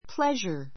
pleasure 中 A1 pléʒə r プ れ ジャ 名詞 楽しみ , 喜び , 愉快 ゆかい , 快楽 Thank you for helping me.—It was a pleasure.